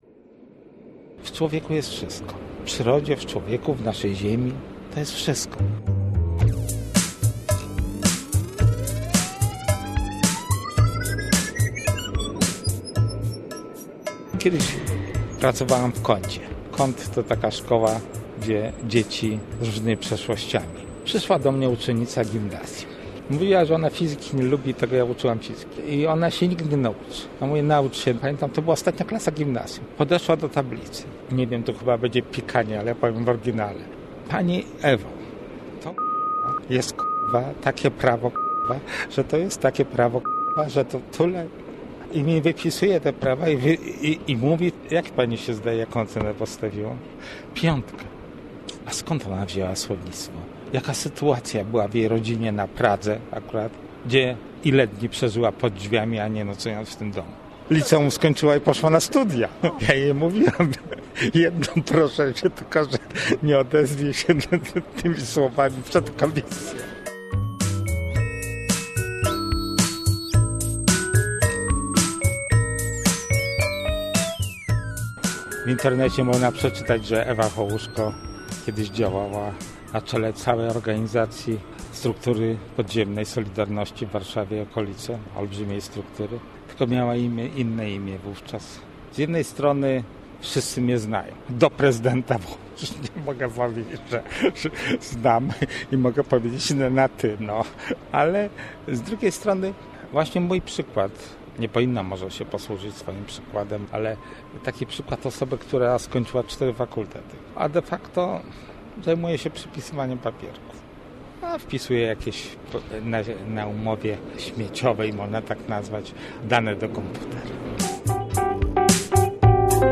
- reportaż